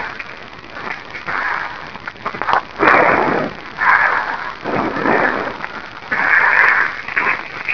EVP 2: Breathing
breathing.wav